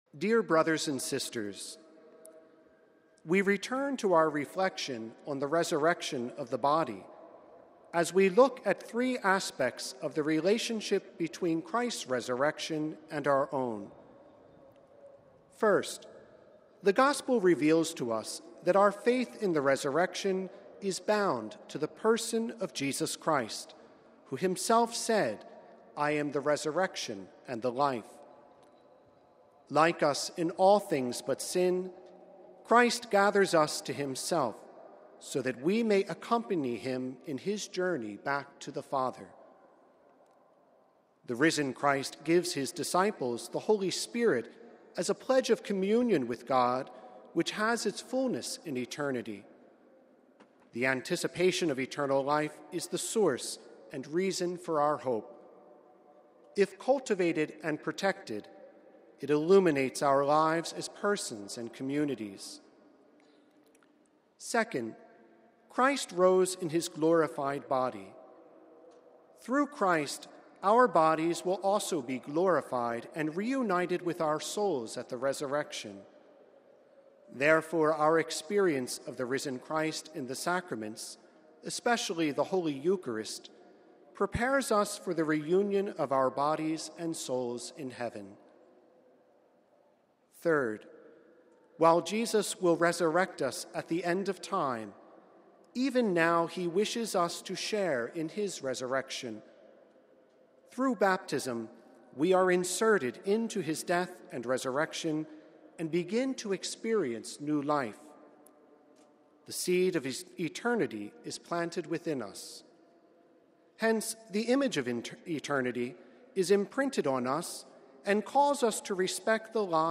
(Vatican Radio) Pope Francis’ catechesis at the General Audience on Wednesday was focused once again on the Creed, as he continued his reflections on “the resurrection of the body.”